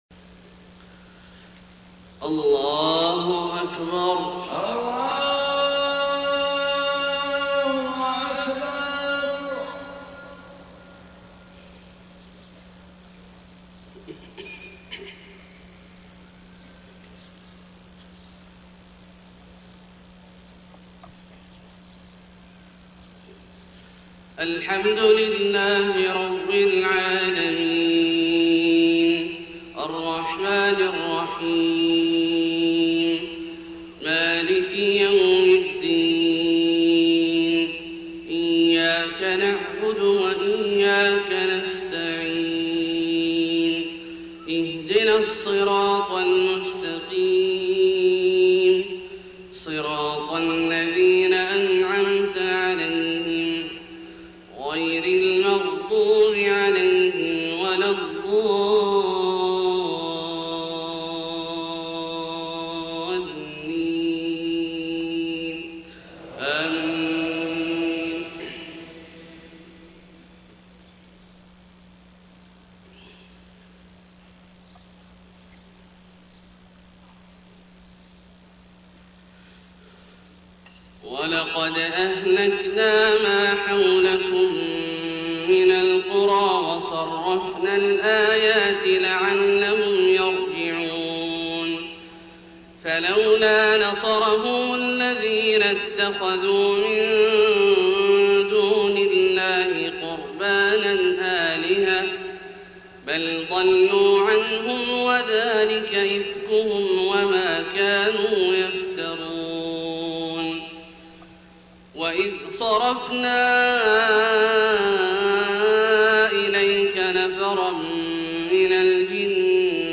صلاة الفجر 7 ربيع الأول 1431هـ خواتيم سورتي الاحقاف {27-35} و الحجرات {13-18} > 1431 🕋 > الفروض - تلاوات الحرمين